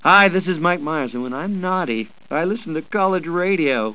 Links   The first five sounds are from a radio I.D. cassette tape Mike Myers did for college radio stations to use. These are all five of the sounds off the tape, which was recorded May 21st 1997 at NSI Sound & Video Inc.